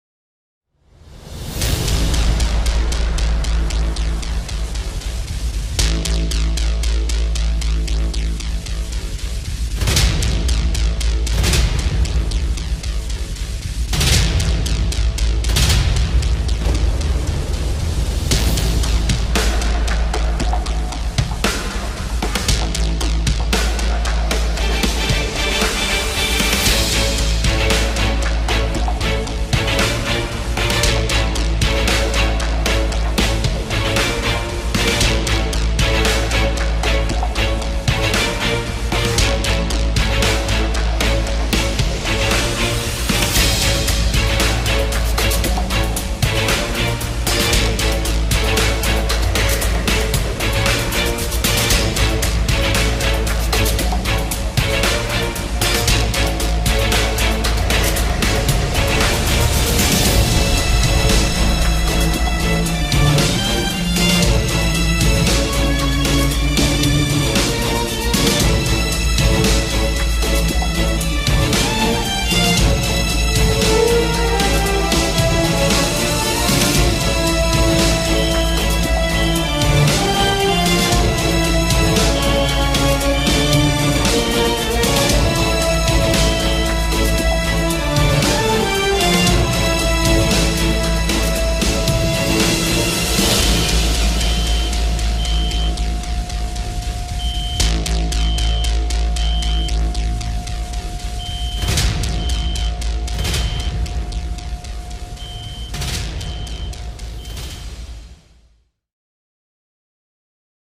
tema dizi müziği, heyecan gerilim aksiyon fon müziği.